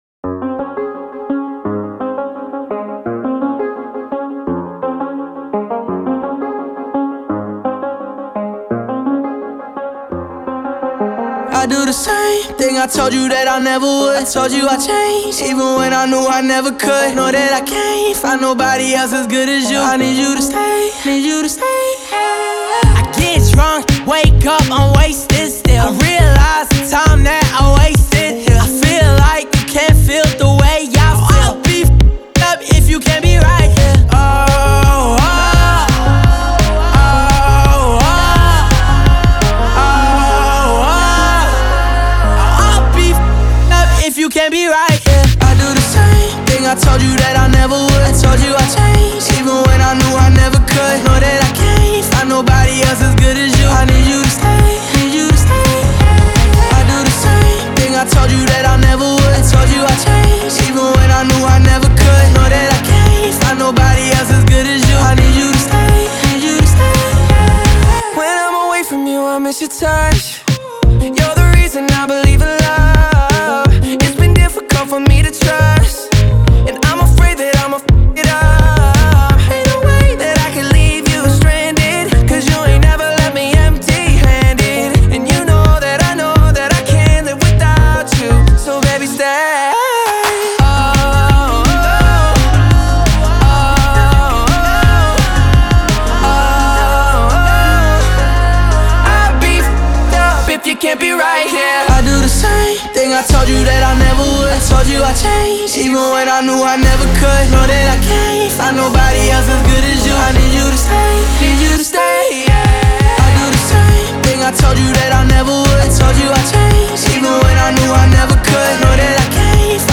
выполненная в жанре поп с элементами хип-хопа.